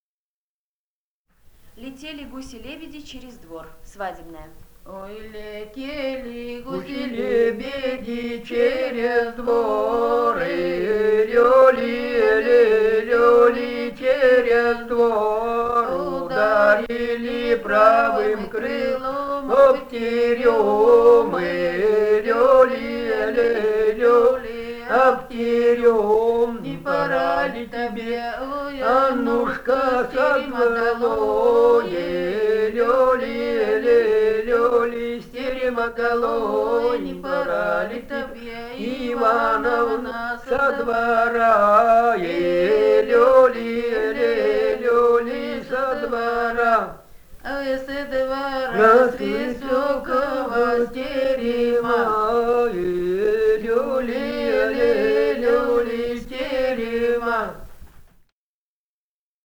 полевые материалы
Алтайский край, с. Михайловка Усть-Калманского района, 1967 г. И1001-06